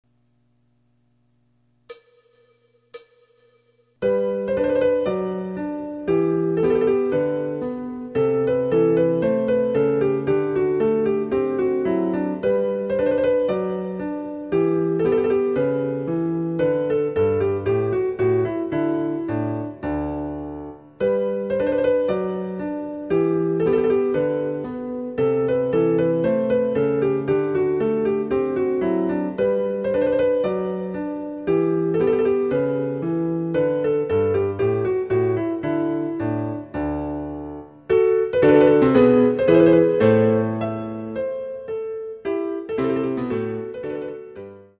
デジタルサンプリング音源使用
※ヴァイオリン奏者による演奏例は収録されていません。